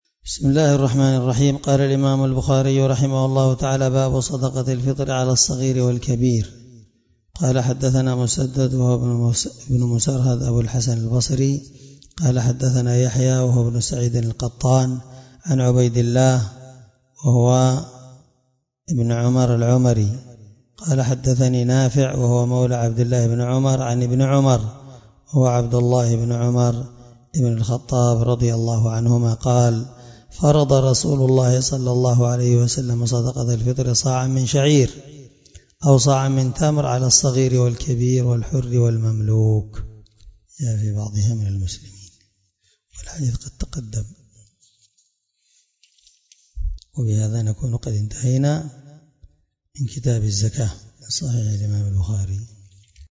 الدرس 70من شرح كتاب الزكاة حديث رقم(1512 )من صحيح البخاري